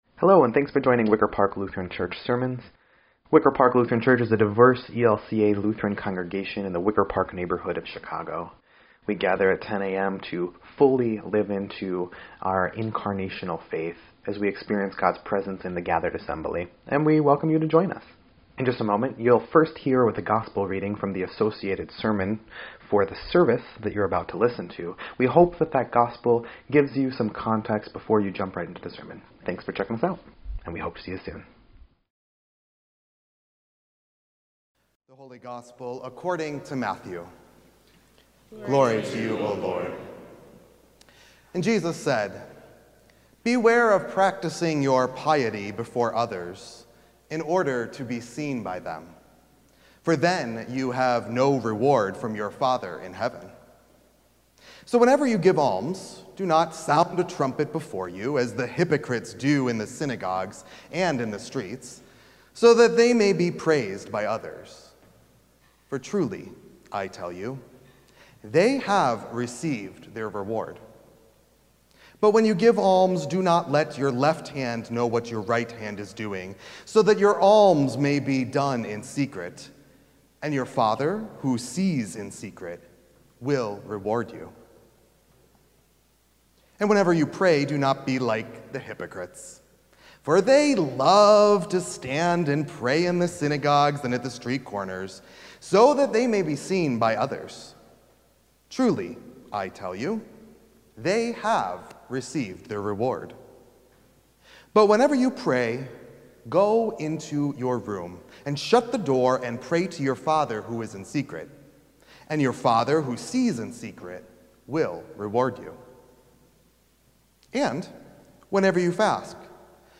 2.18.26-Sermon_EDIT.mp3